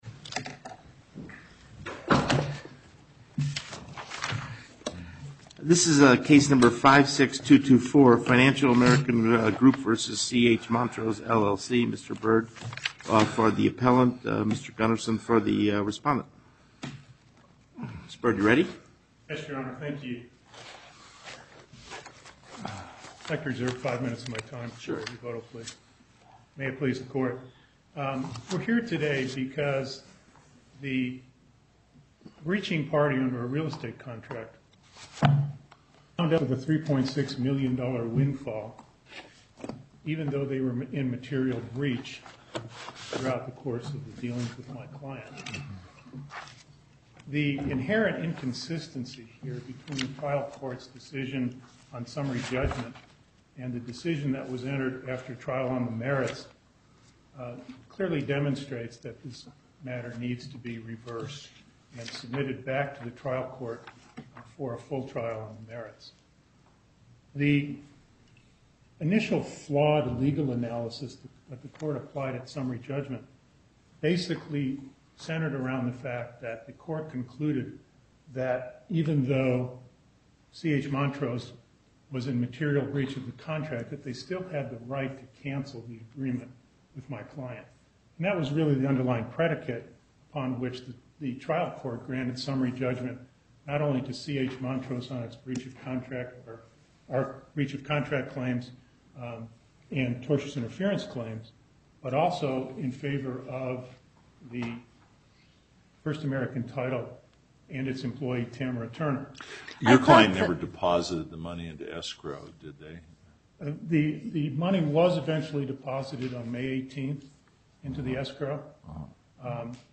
Location: Las Vegas Northern Nevada Panel, Justice Cherry Presiding